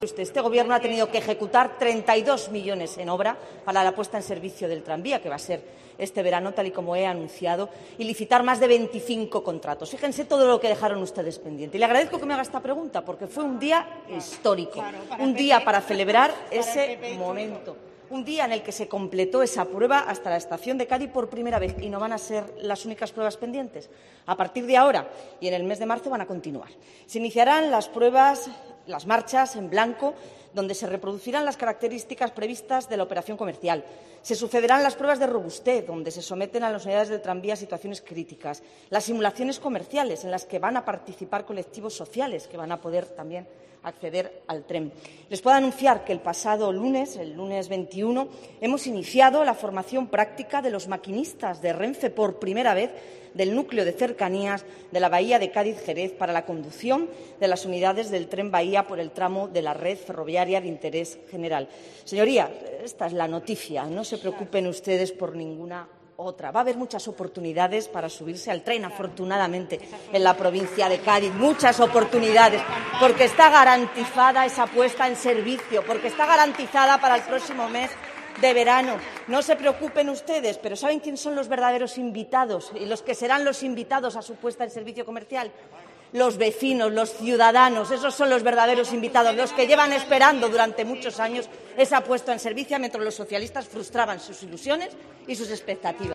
La Consejera de Fomento, se pronuncia en el Parlamento andaluz sobre el Tranvía Metropolitano de la Bahía